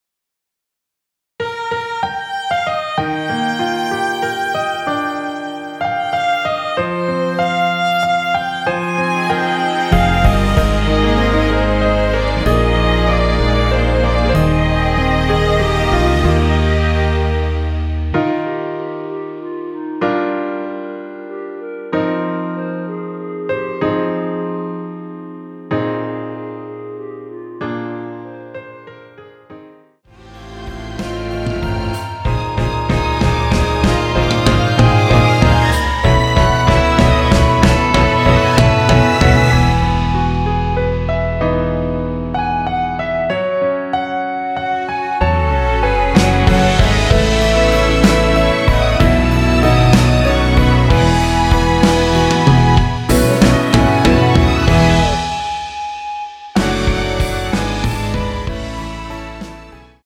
원키에서(+4)올린 멜로디 포함된 MR입니다.(미리듣기 확인)
Eb
앞부분30초, 뒷부분30초씩 편집해서 올려 드리고 있습니다.
중간에 음이 끈어지고 다시 나오는 이유는